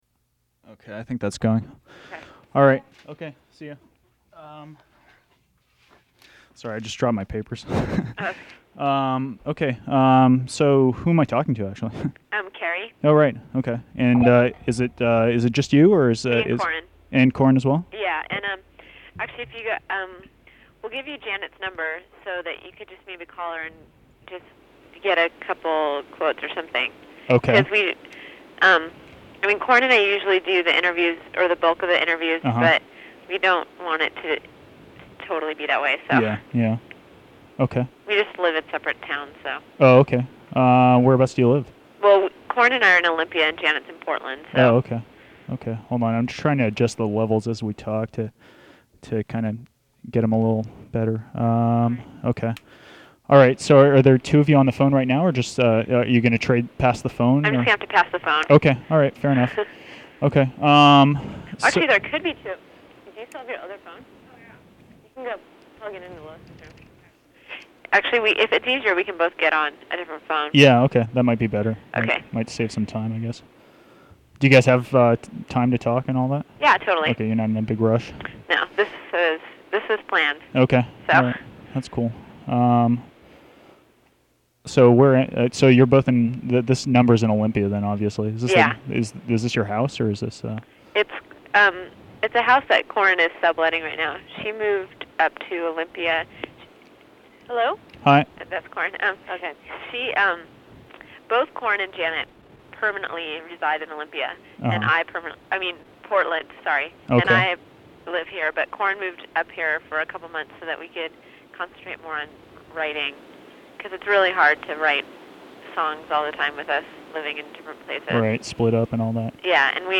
phone interview